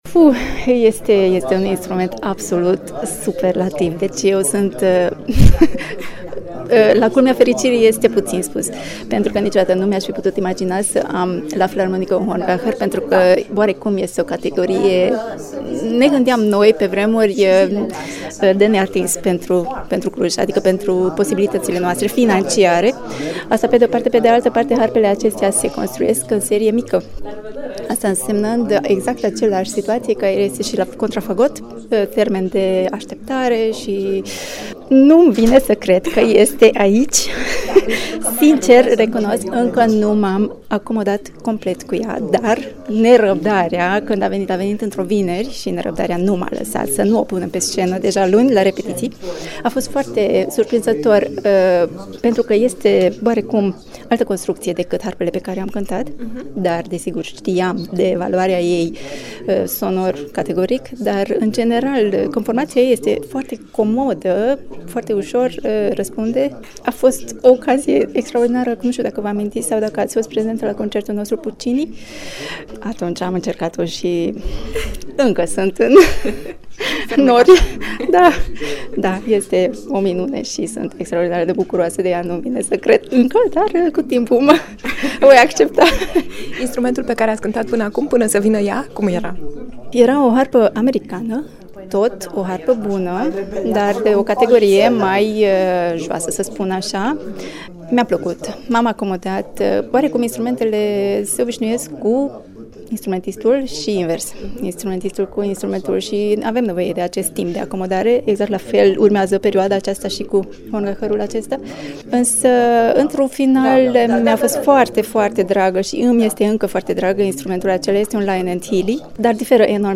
harpista.mp3